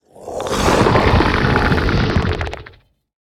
mob / warden / roar_1.ogg
roar_1.ogg